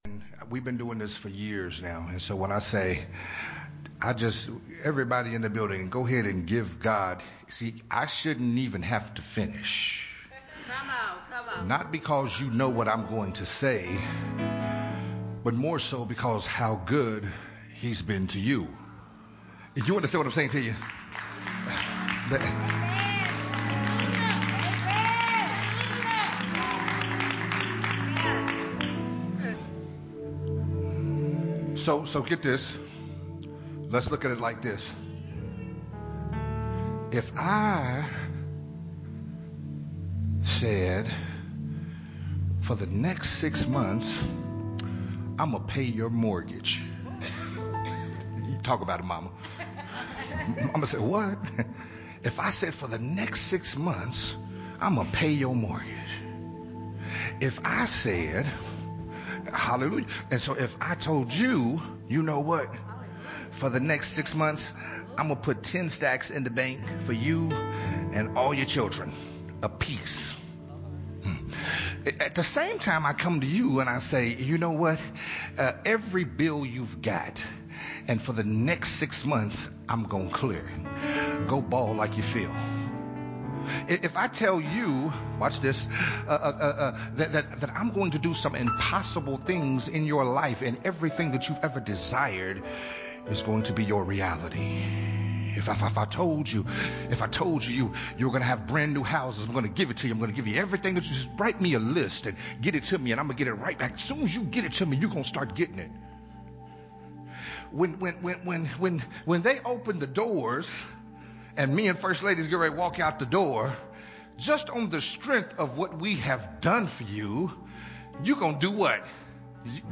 This sermon, Part 1, was recorded at Unity Worship Center on 5/23/2021.